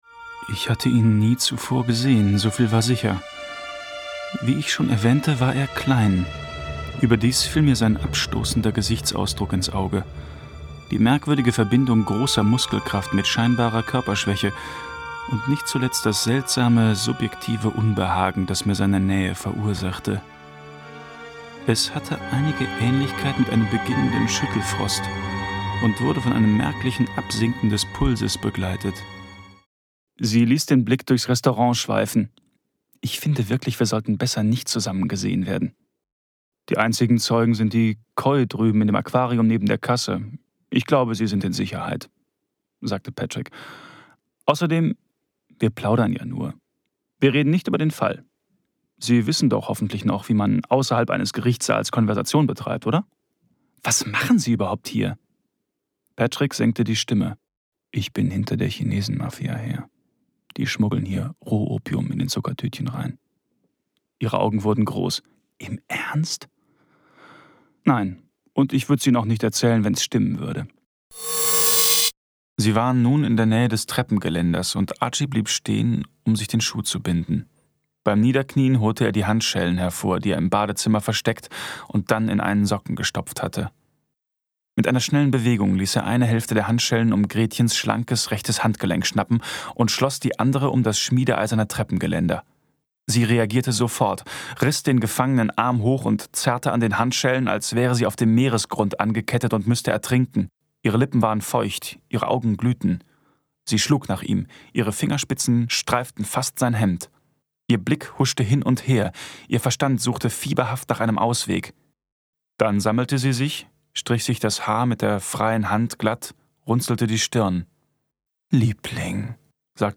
Sprecher deutsch. Frische, klare, direkte Stimme.
Sprechprobe: Industrie (Muttersprache):
voice over artist german